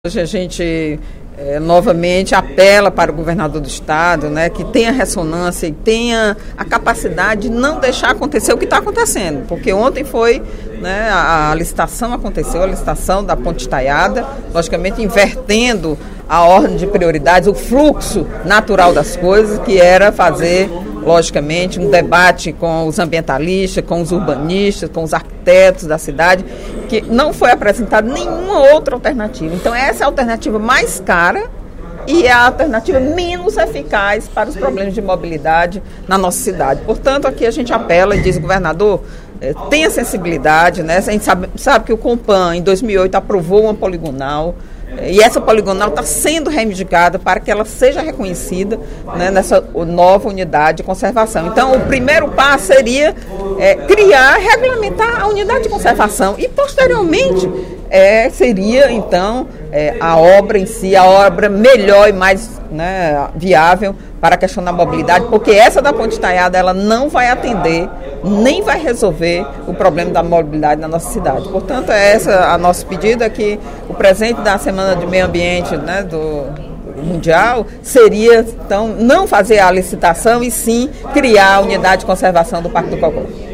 A deputada Eliane Novais (PSB) contestou, nesta quinta-feira (06/06), durante o primeiro expediente, a decisão do Governo do Estado de realizar a licitação para construção da ponte estaiada sobre o rio Cocó.